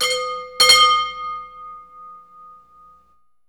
Index of /90_sSampleCDs/E-MU Producer Series Vol. 3 – Hollywood Sound Effects/Ambient Sounds/Gas Station
GAS STATI00L.wav